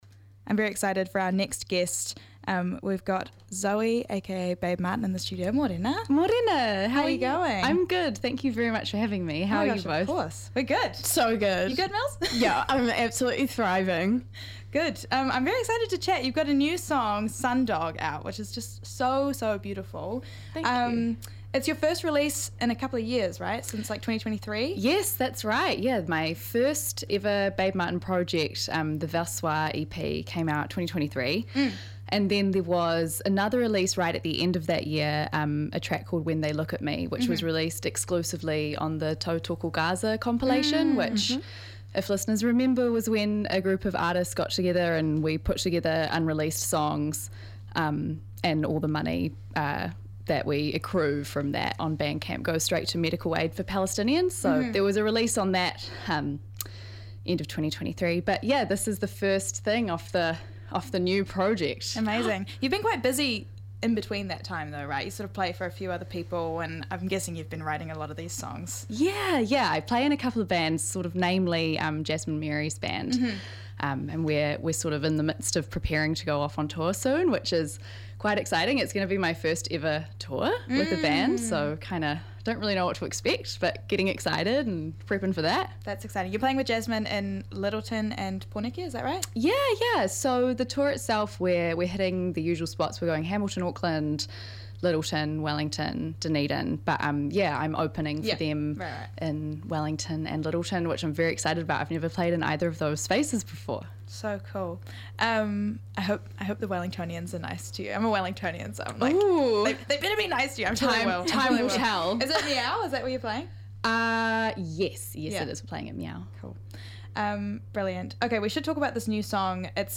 Guest Interview w